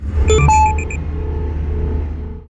drone-sound_24685.mp3